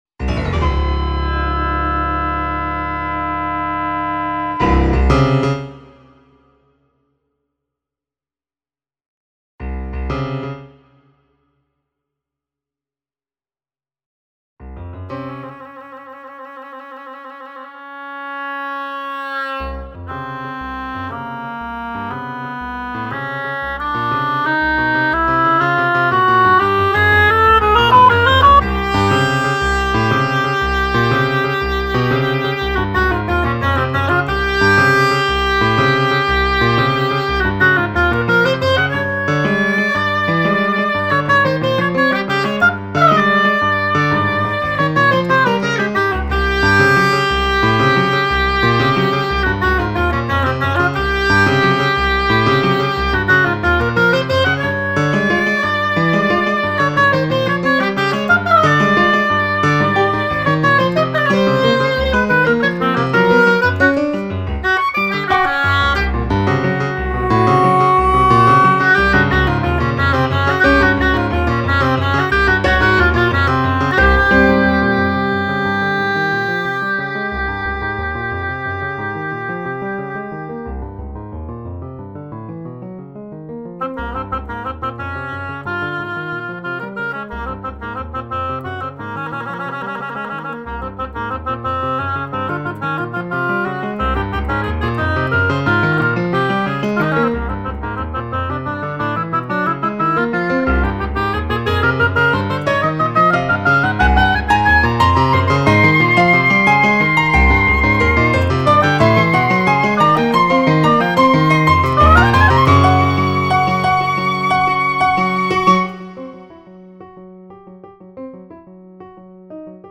oboe & piano